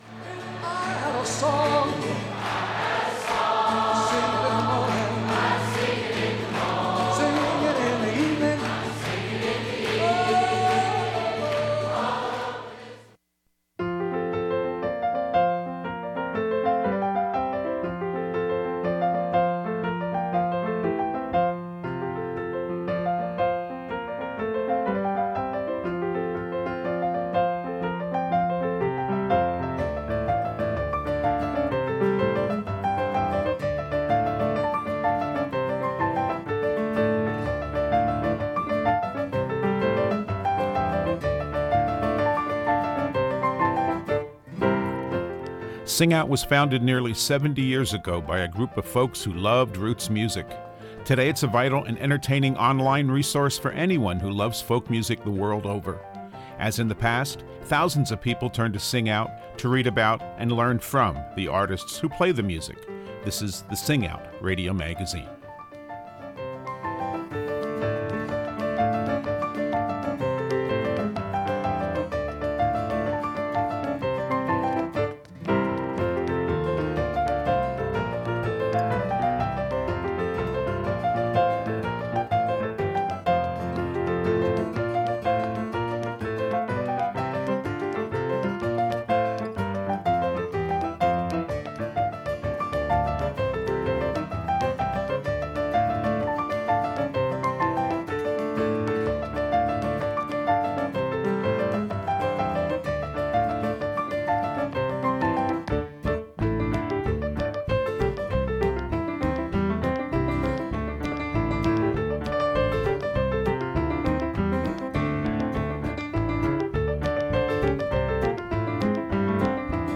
This week on The Sing Out! Radio Magazine we begin a two-part feature commemorating Women's History Month. We'll hear some wonderful music from musicians and singers who just happen to be women.